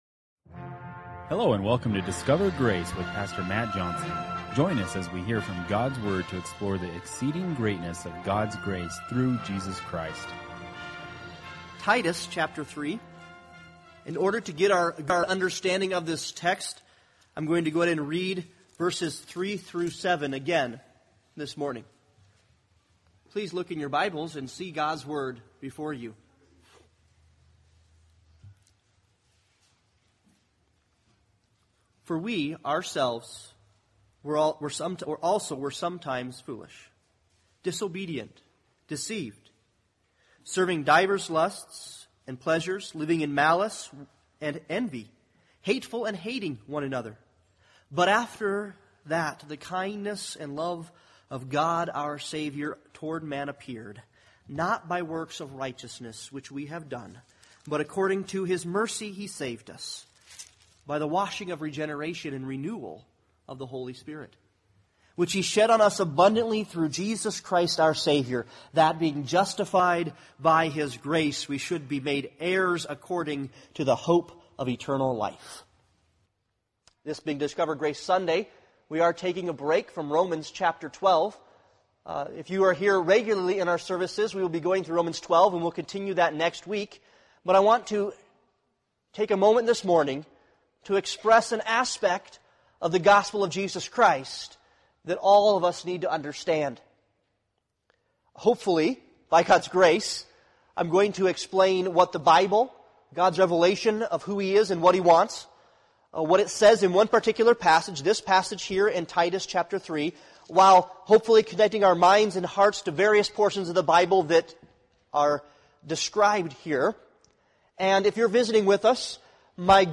Titus 3:3-7 Service Type: Sunday Morning Worship « Dare To Be Ordinary